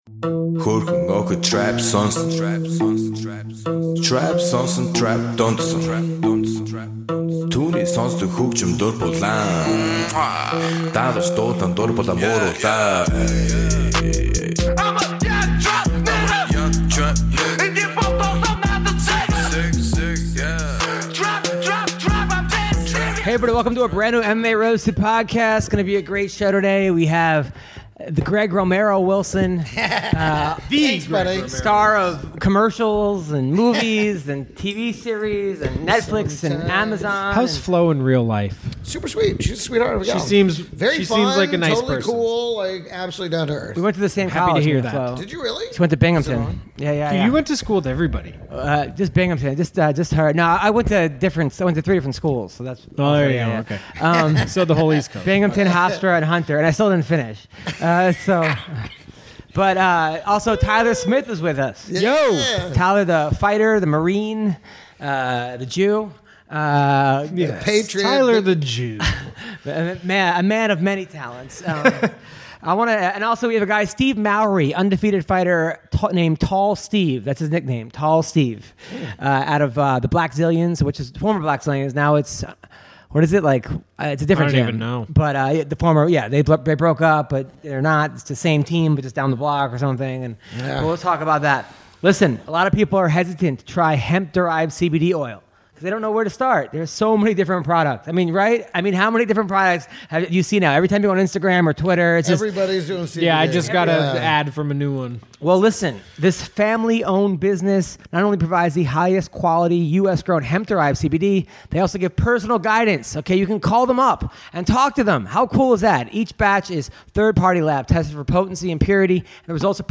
joined in studio
The crew takes a call from MMA fighter